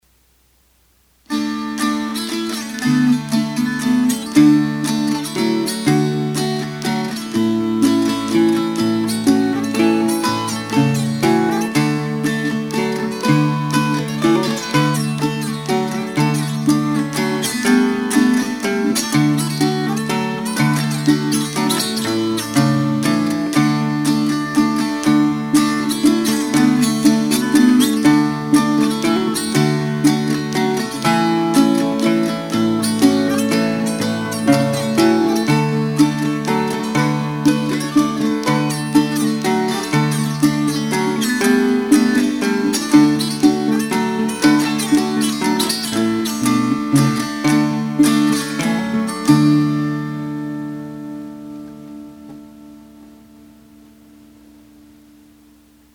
Gardners Dulcimer Shop - About our Dulcimers
These dimensions give our instruments a full rich tone.
• Listen to samples of our Dulcimers being played one at a time to hear 2 different songs: